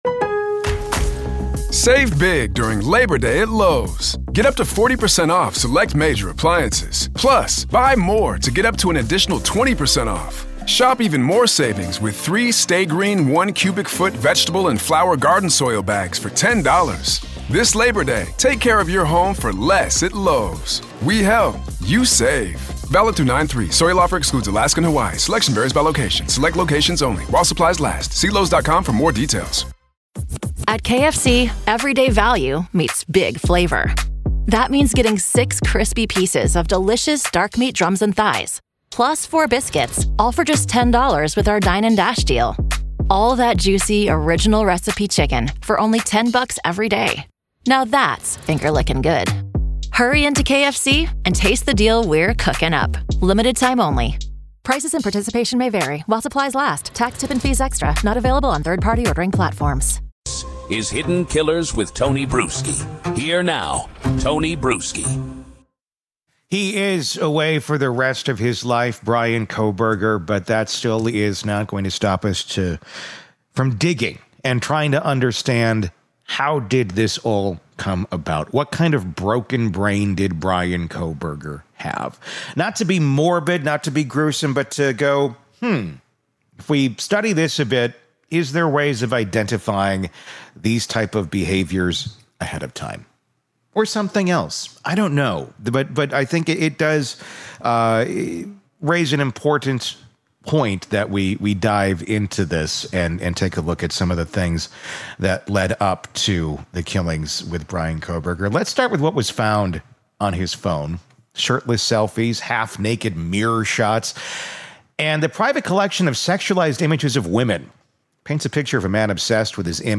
We break the interview into four chapters — each tackling a different piece of the puzzle — and in this combined cut you get the entire discussion uninterrupted.